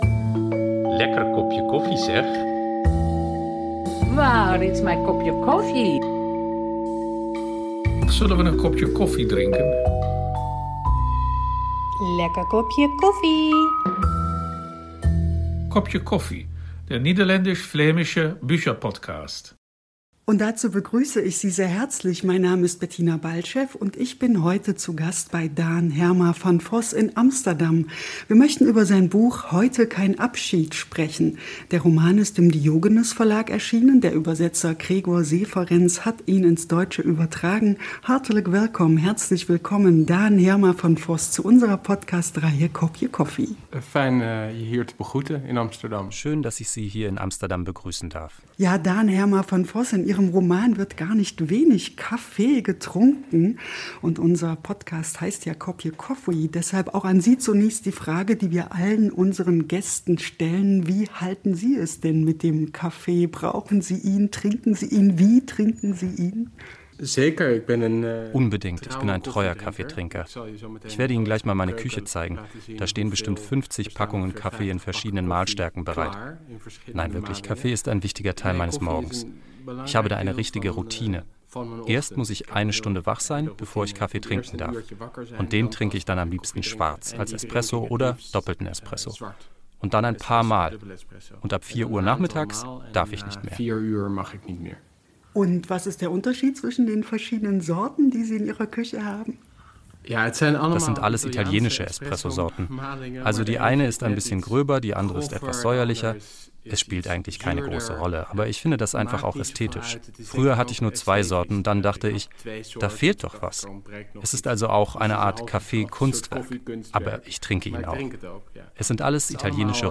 Dabei sind auch Auszüge in Originalsprache und viel Persönliches von ihren Gästen zu hören.